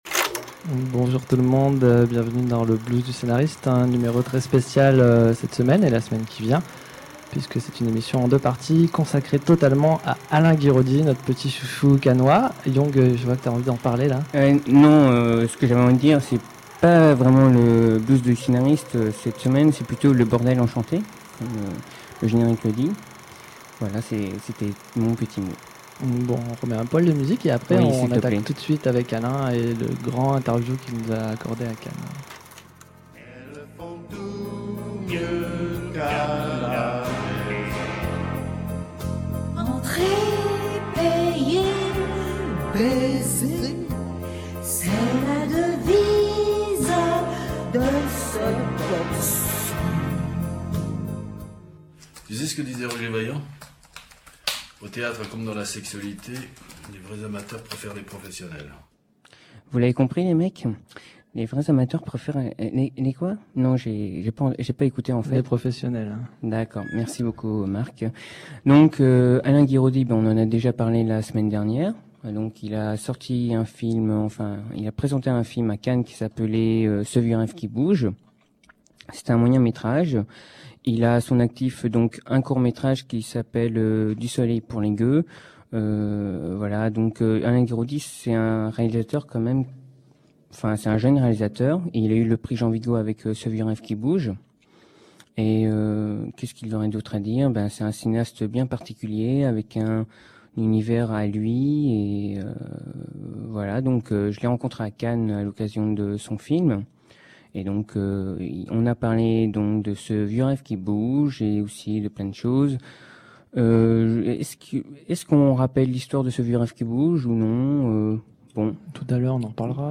En 2007, Hors-Champ abordait le thème des morts-vivants dans le cinéma. En 2008, Paris Cinema enregistrait l'entretien mené avec le cinéaste finlandais Aki Kaurismaki.